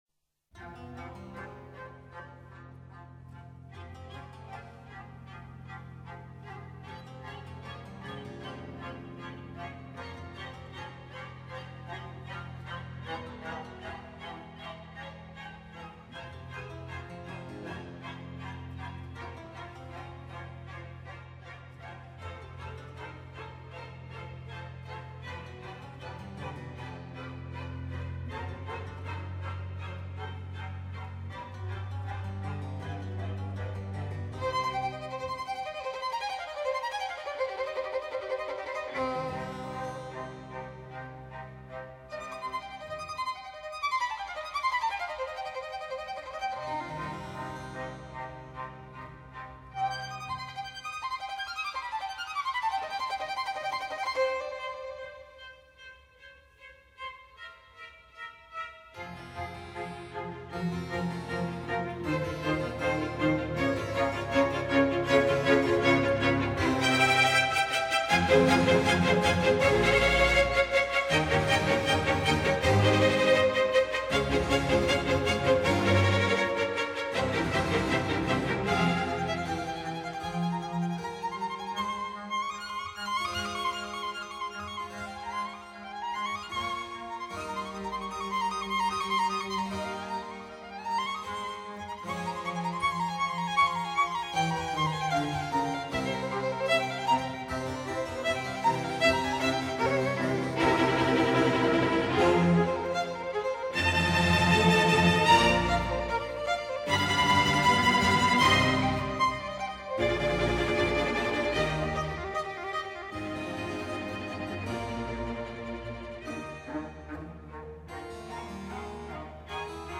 独奏小提琴中的飞速的琶音和音阶描绘“可怕的烈风”之严峻，一串强劲有力的和弦令人联想到快速奔跑和跺脚。